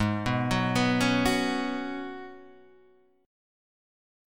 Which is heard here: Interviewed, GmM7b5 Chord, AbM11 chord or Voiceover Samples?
AbM11 chord